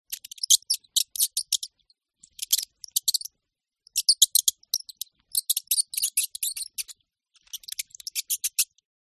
Все записи натуральные и четкие.
писк мыши